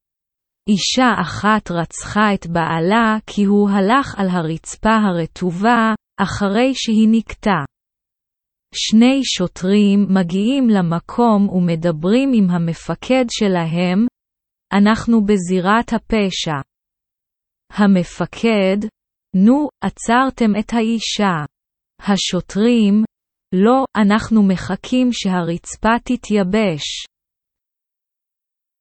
Texte Hébreu lu à haute voix à un rythme lent !